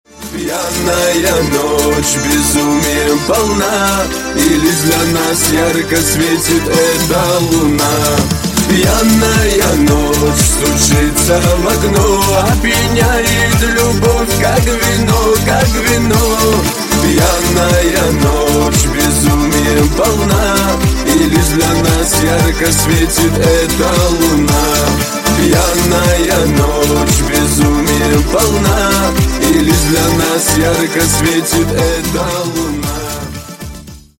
Кавказские Рингтоны
Шансон Рингтоны